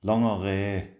hochdeutsch Gehlbergersch
Langer Rain  Lange Rää